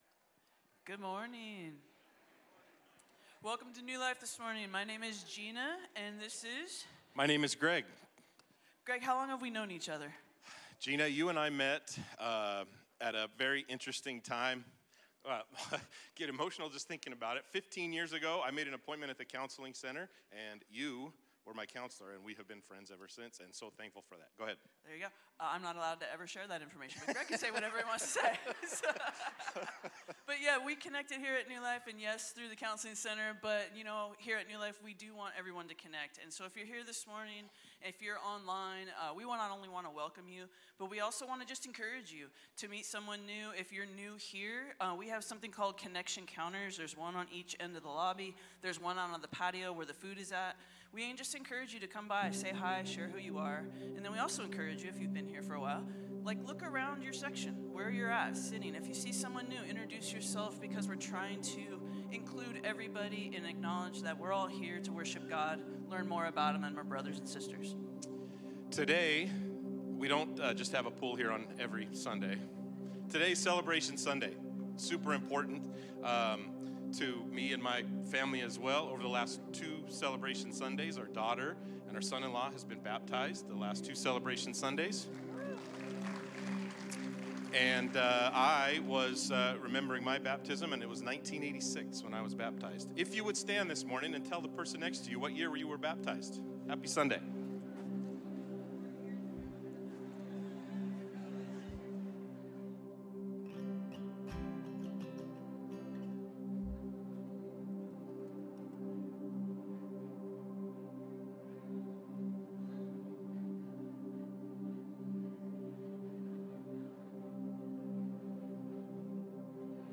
Sermons not tied to a larger series